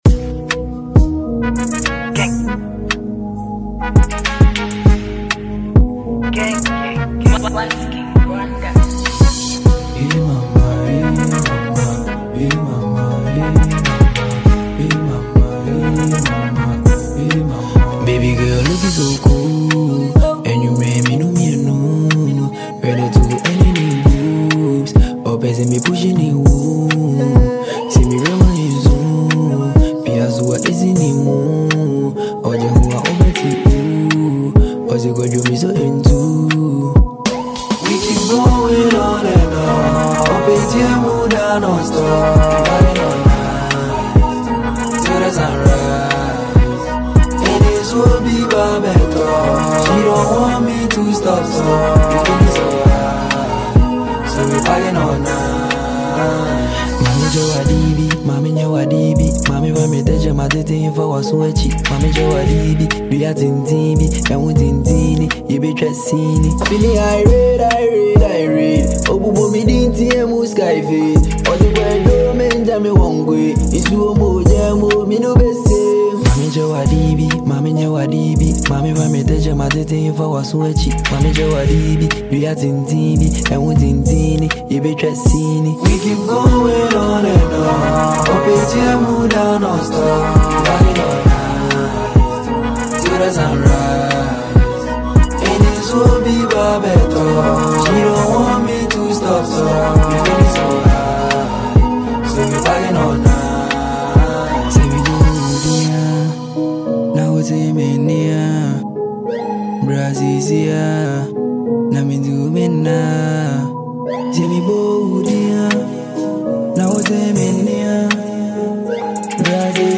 With his signature drill energy and sleek flow
Genre: Drill / Kumerican